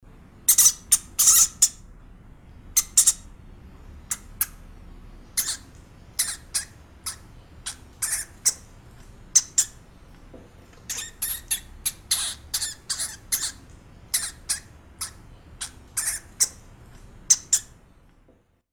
Здесь собраны самые забавные и жизненные аудиозаписи: от веселого похрустывания корма до довольного попискивания.
Звуки хомяка: Почему хомяк пищит